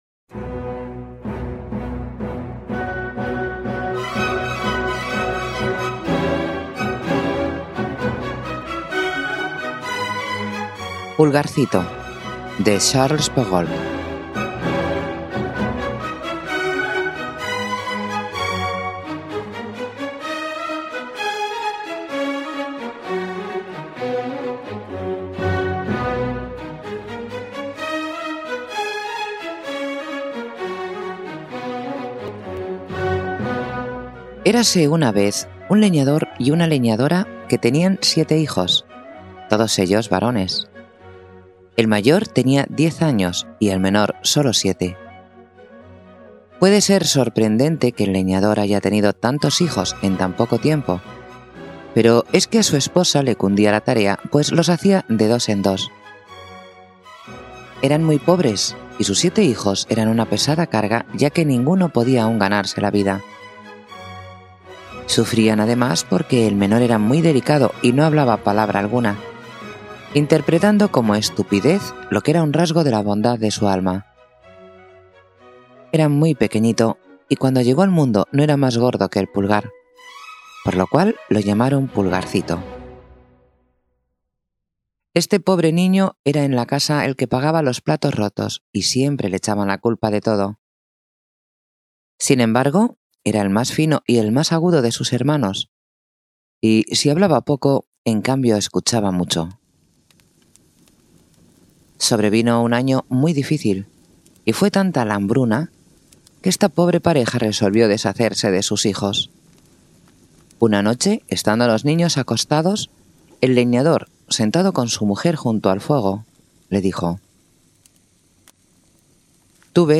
Audiolibro: Pulgarcito
Cuentos clásicos infantiles
Música: OpenMus